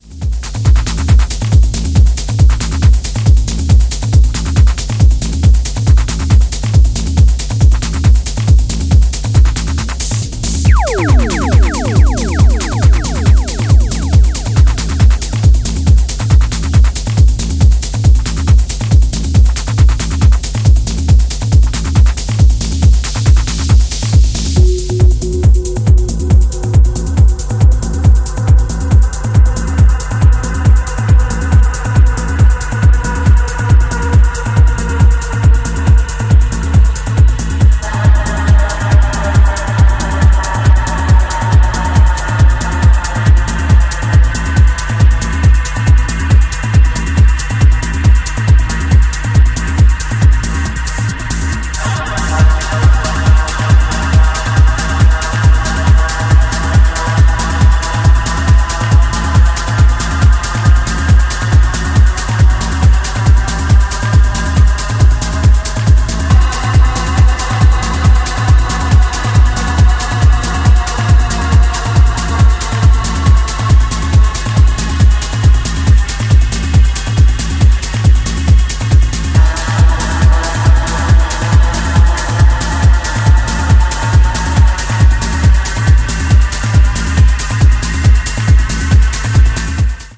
four hard-hitting techno cuts